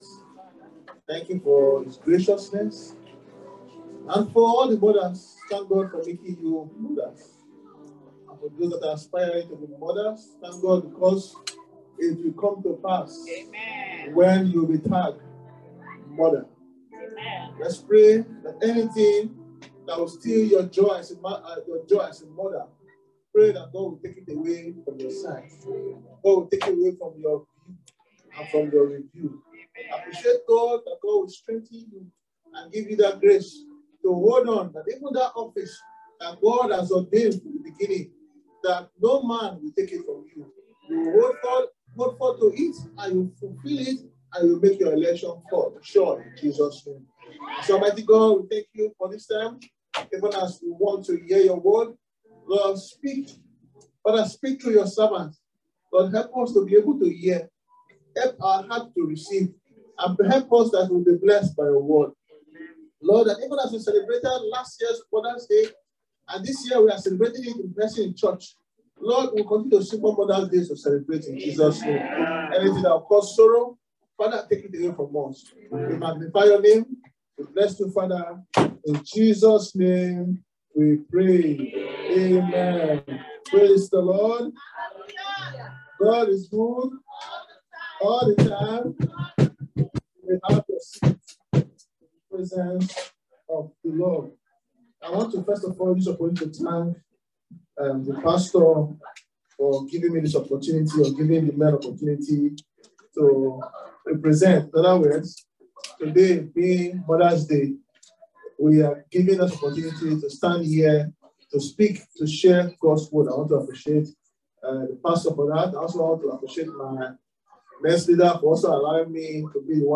John 19:26-27 Service Type: Sunday Service Today is Mother’s Day!